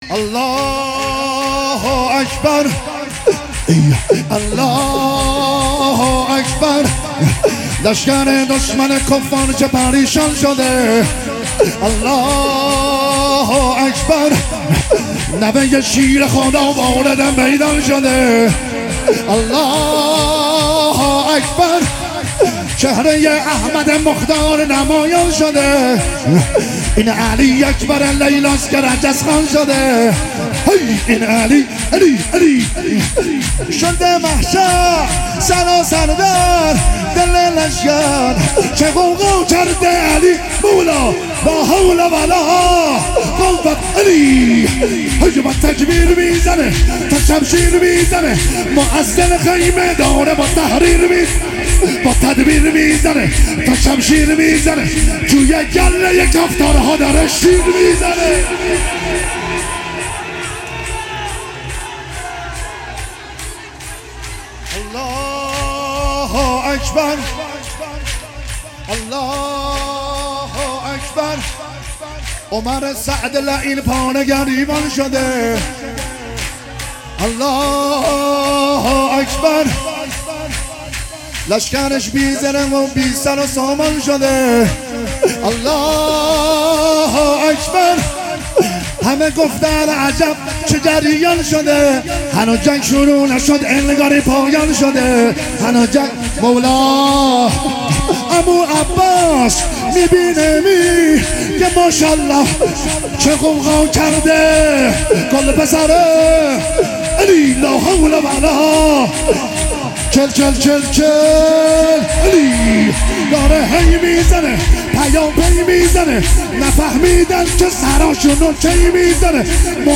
مولودی حضرت علی اکبر
ولادت حضرت علی اکبر علیه السلام 1401